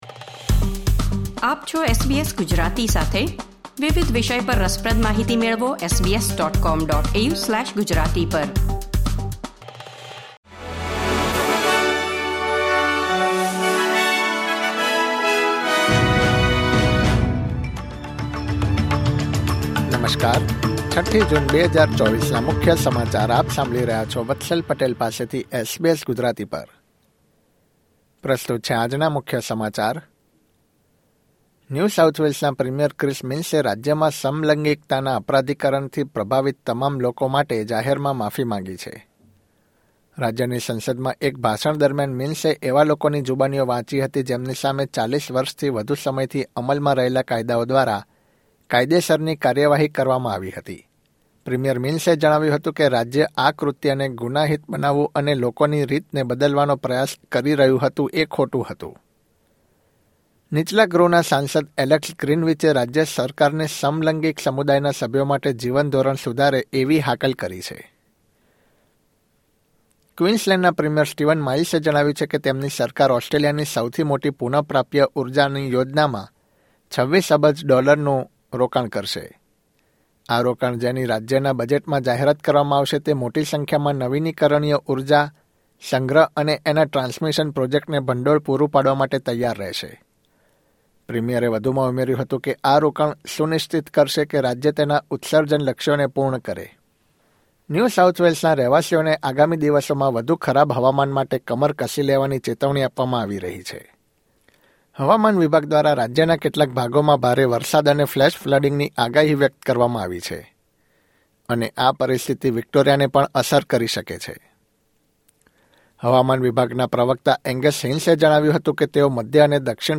SBS Gujarati News Bulletin 6 June 2024